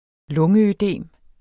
Udtale [ ˈlɔŋə- ]